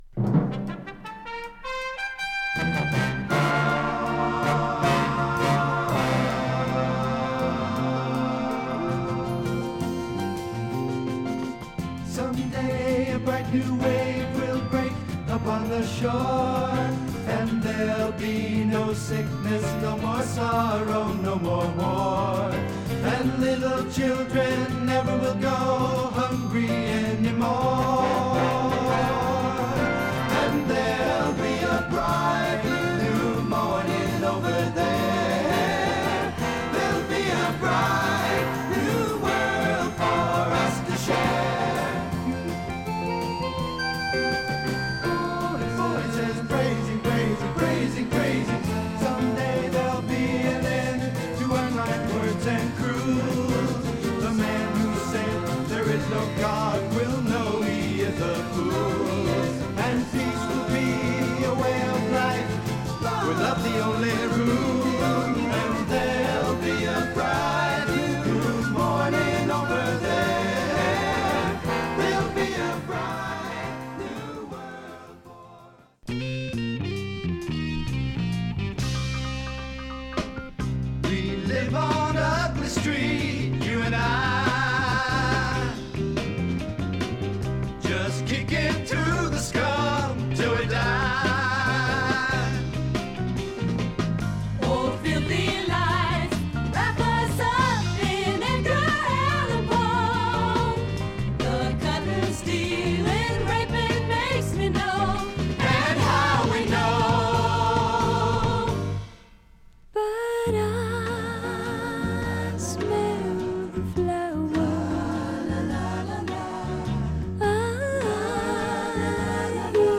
男女混声レリジャス・ソフトロックの名作♪